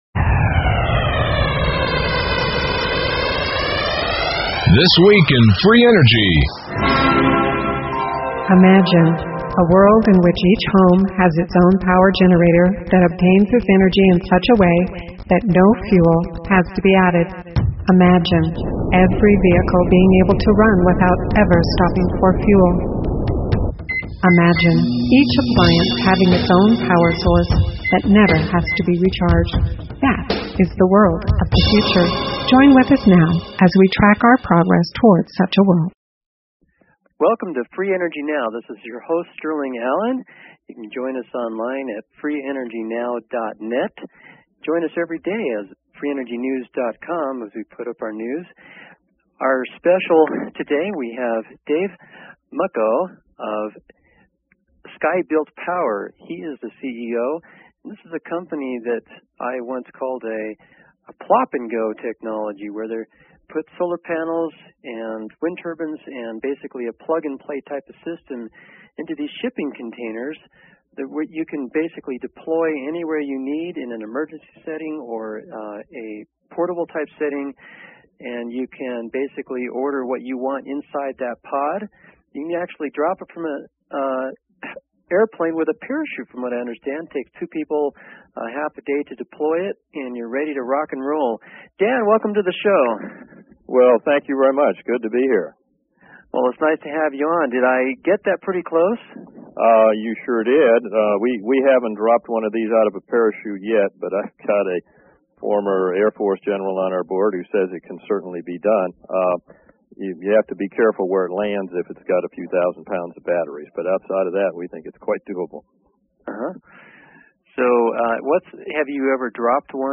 Talk Show Episode, Audio Podcast, Free_Energy_Now and Courtesy of BBS Radio on , show guests , about , categorized as